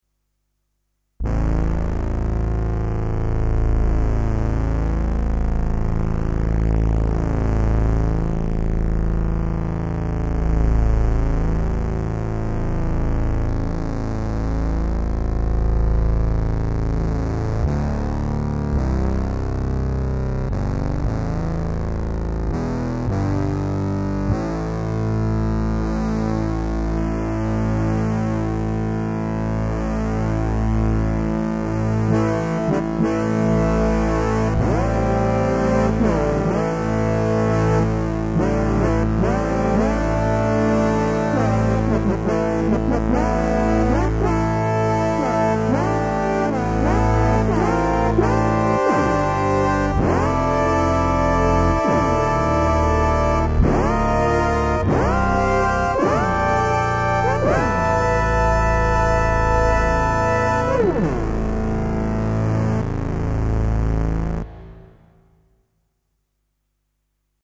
The Yamaha DX7 digital programmable algorithm synthesizer.
Another program of analog style sounds.
Drone.mp3